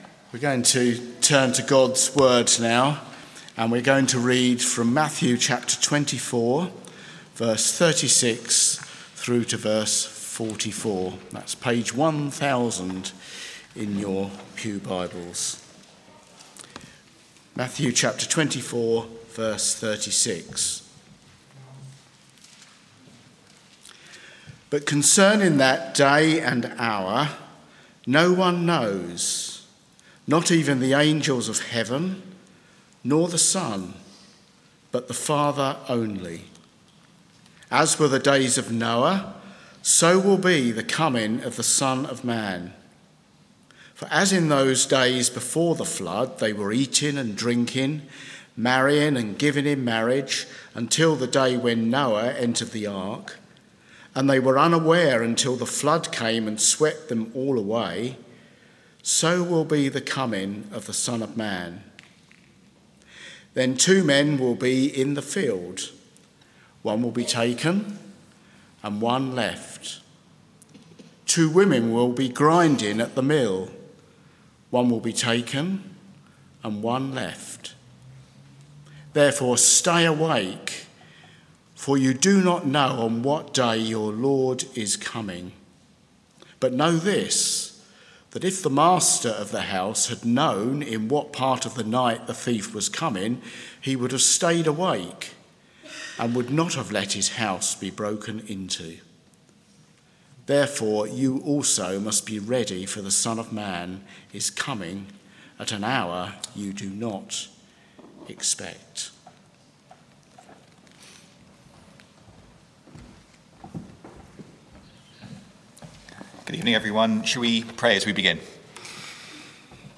Media for Evening Meeting on Sun 01st Dec 2024 18:00 Speaker
Matthew 24 Sermon - Audio Only Search media library...